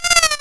raindrop_placeholder.wav